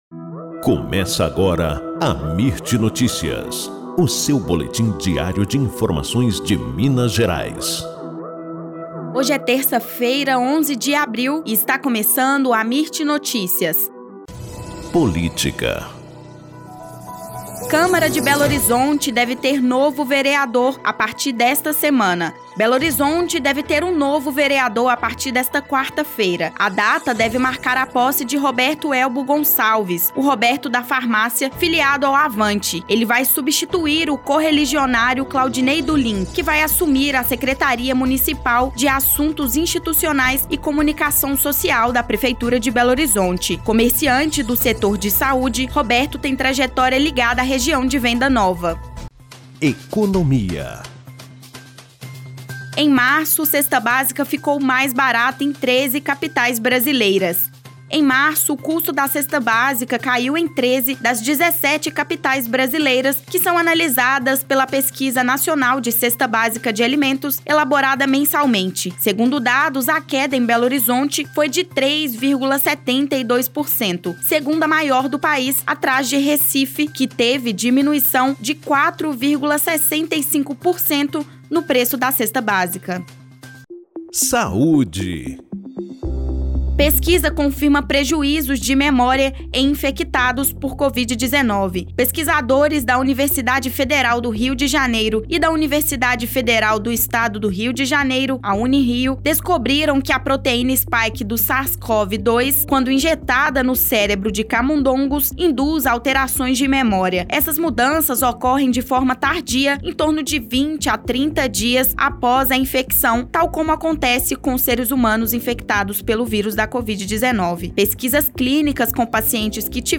Boletim Amirt Notícias – 11 de abril